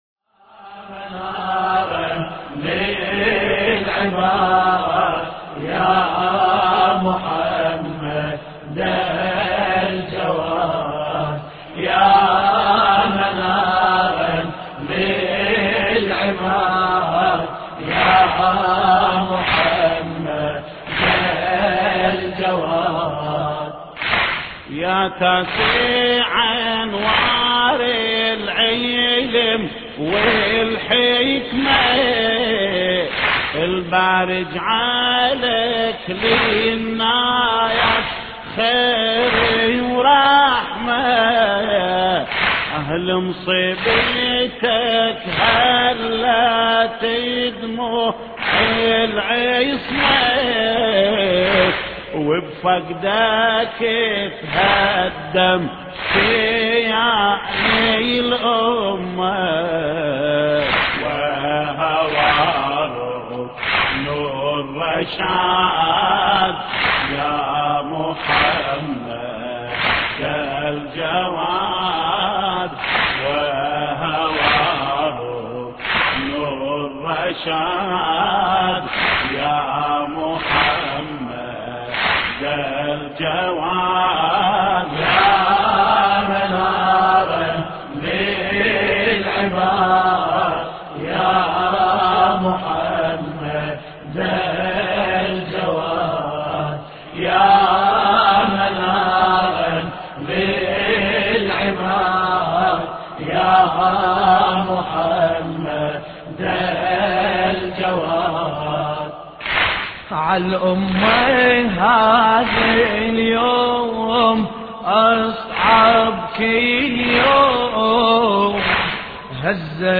مراثي الامام الجواد (ع)